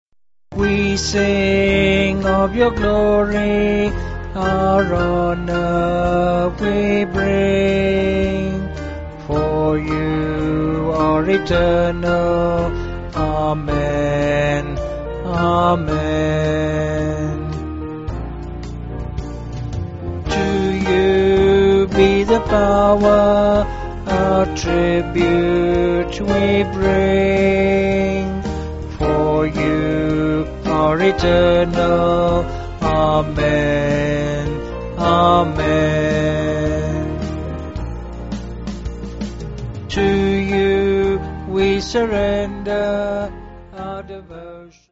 (BH)   4/Eb-E
Vocals and Band